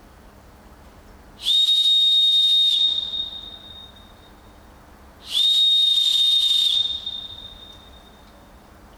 Fox 40 Micro whistle